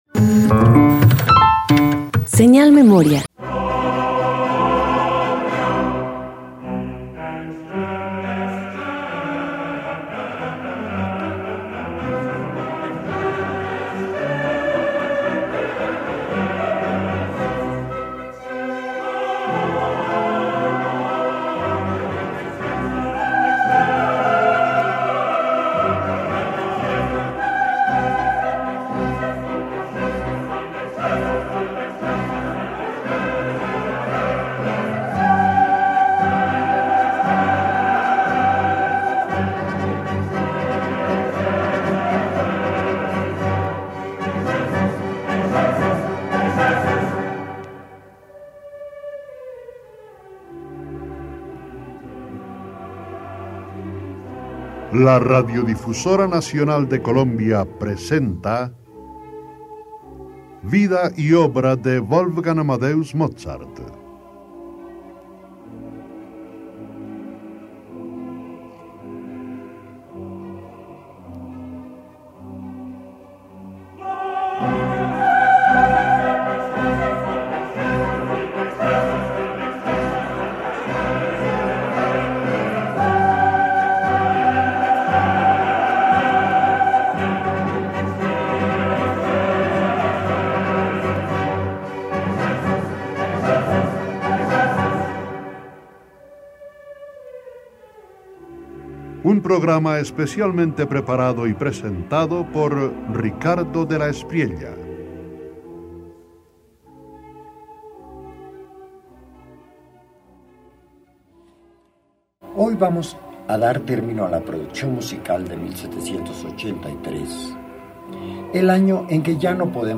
Radio colombiana